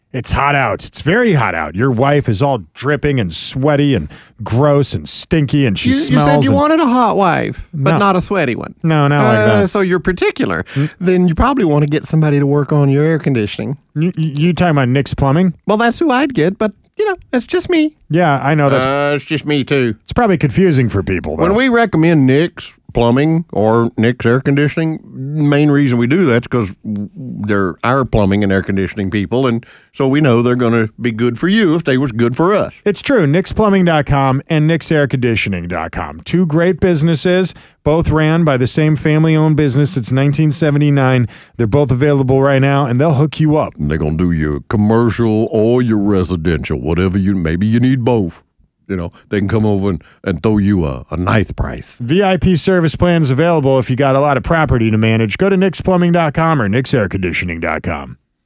Nick’s Plumbing Radio Ads
Explore and listen to a collection of our best plumbing ads that have aired in the greater Houston region.